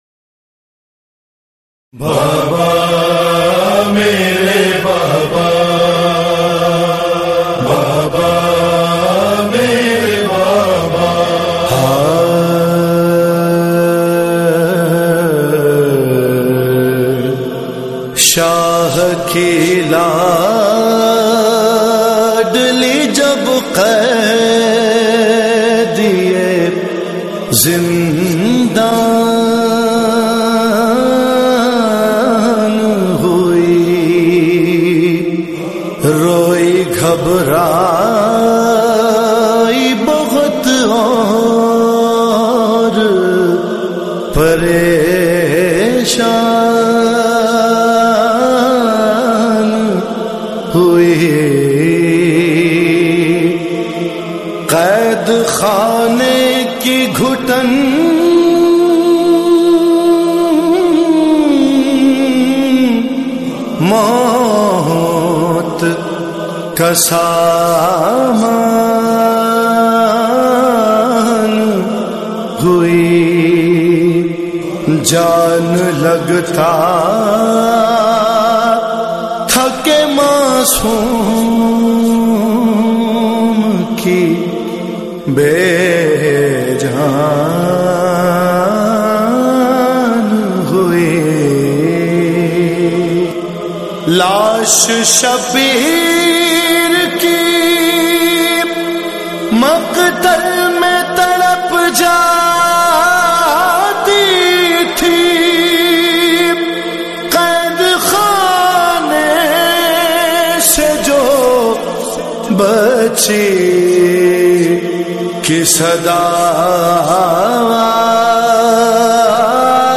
noha